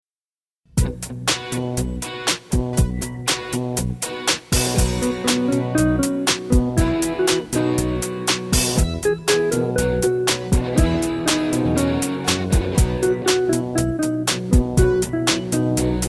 *  Catchy melodies, dumb jokes, interesting stories